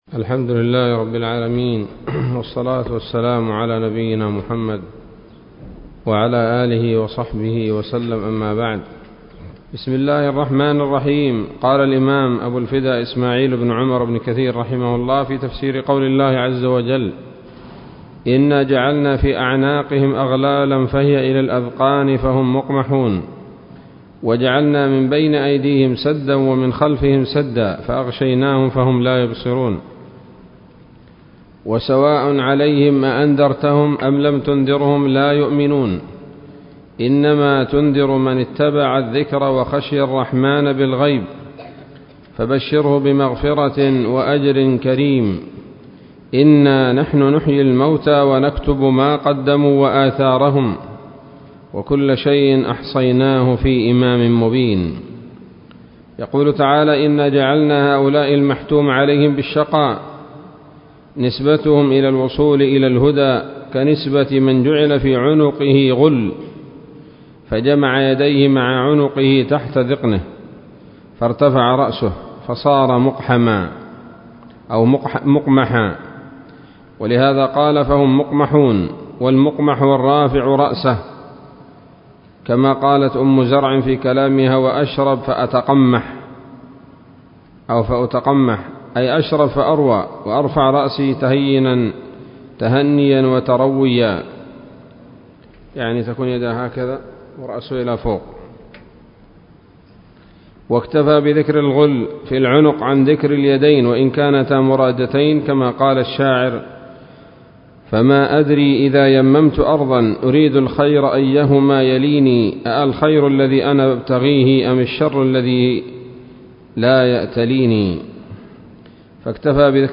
الدرس الثاني من سورة يس من تفسير ابن كثير رحمه الله تعالى